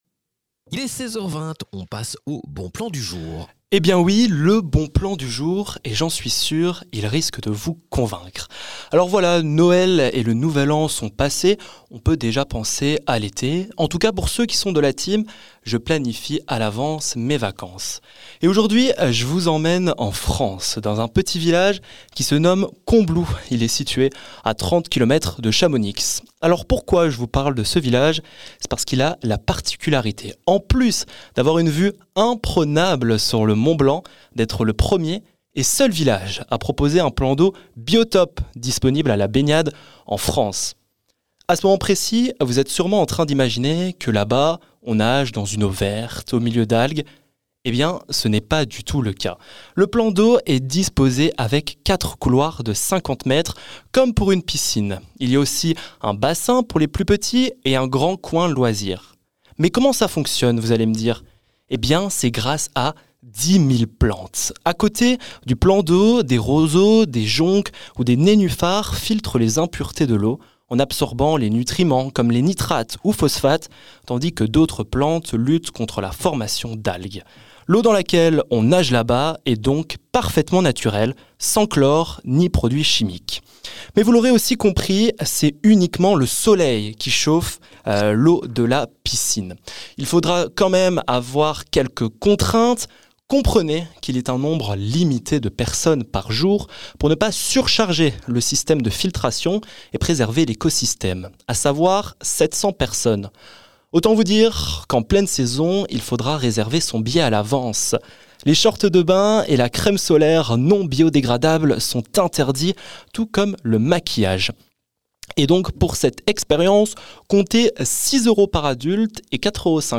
Chroniques